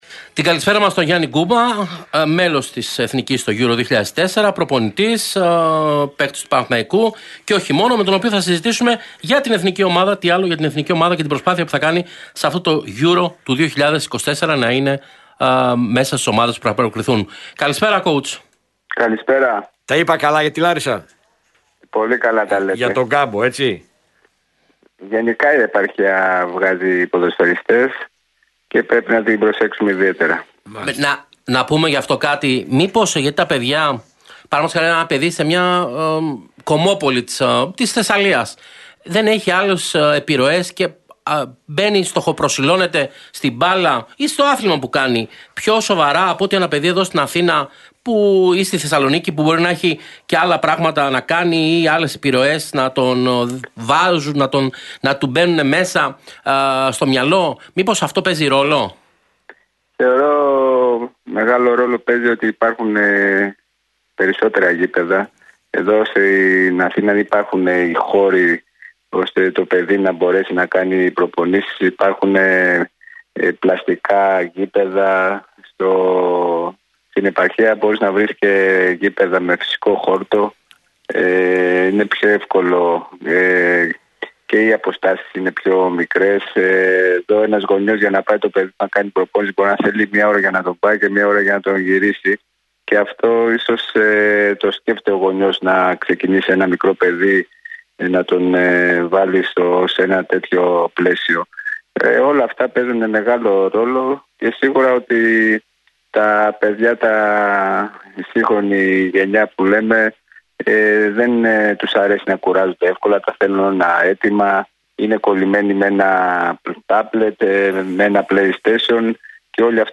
μιλώντας στην εκπομπή Realsports του «Real Fm 97.8»